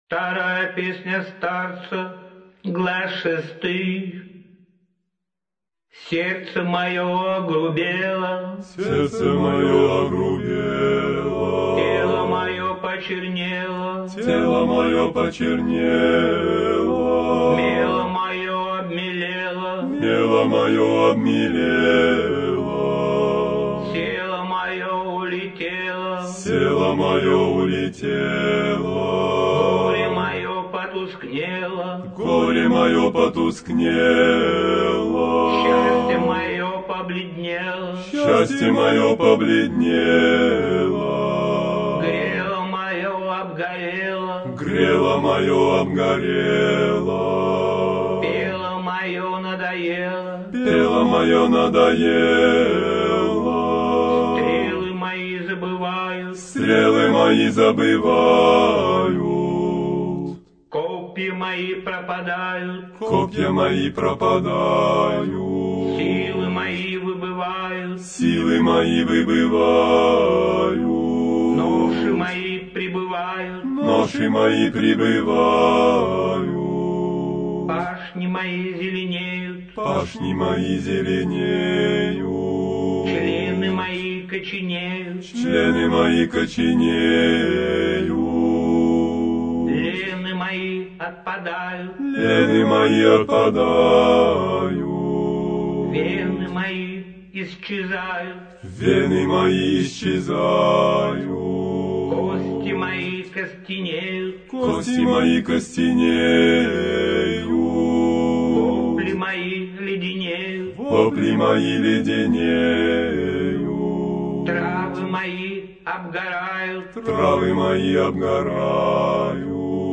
Всю композицию (mono, 40 kbps, 907 kb) вы можете скачать